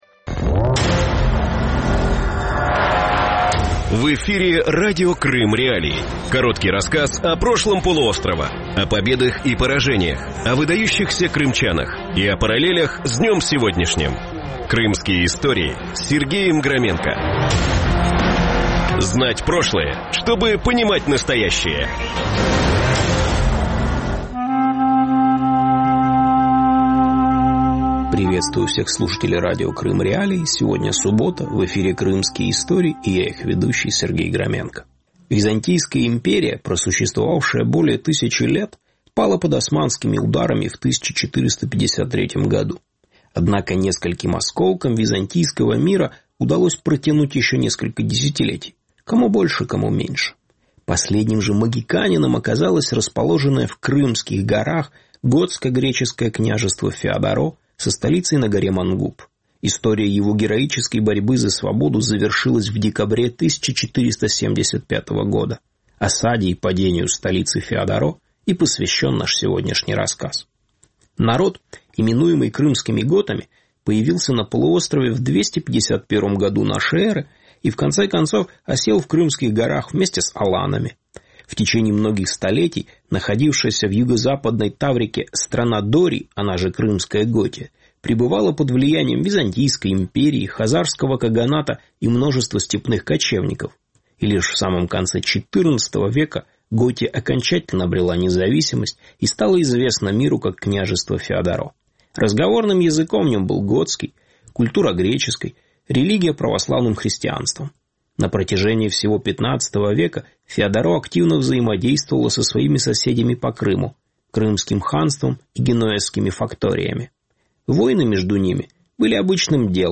Эфир можно слушать на сайте Крым.Реалии, а также на средних волнах на частоте 549 килогерц.